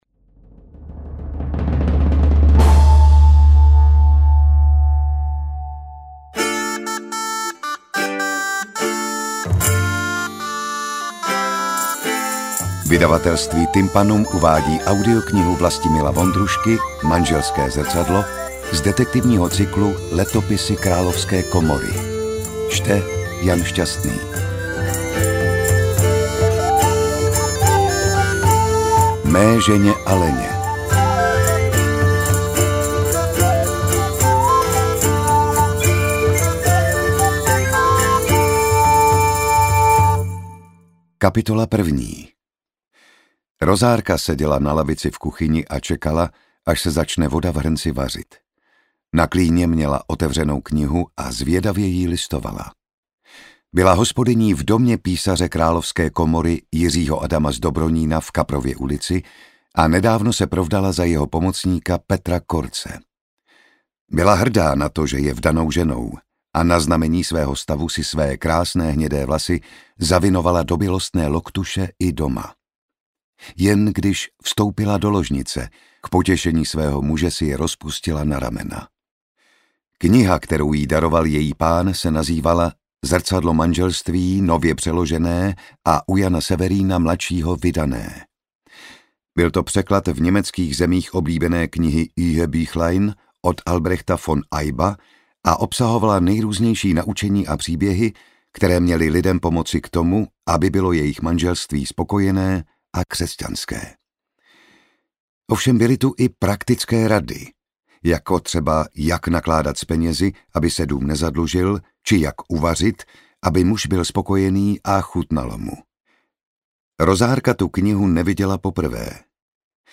Interpret:  Jan Šťastný